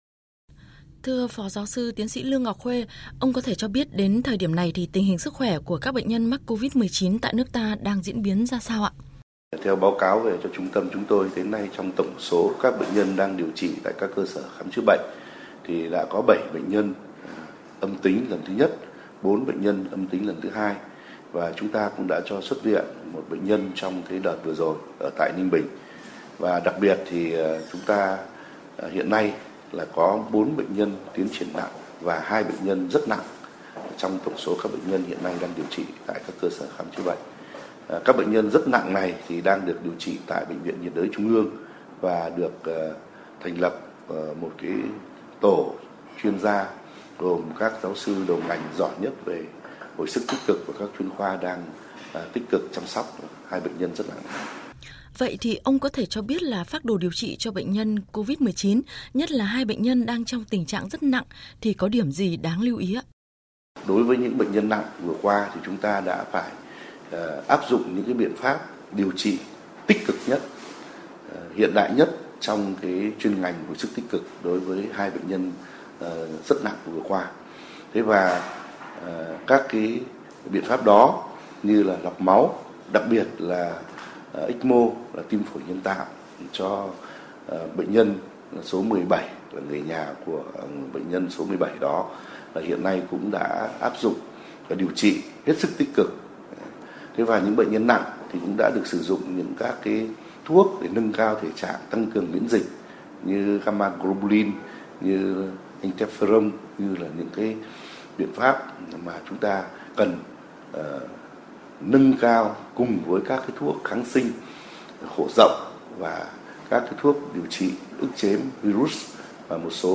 Vậy công tác điều trị cần tập trung vào những điểm gì? Phóng viên Đài TNVN đã phỏng vấn GS-TS Lương Ngọc Khuê, Cục trưởng Cục quản lý khám chữa bệnh, Giám đốc Trung tâm quản lý điều hành trực tuyến hỗ trợ chuyên môn, chuẩn đoán điều trị Covid-19 Bộ Y tế.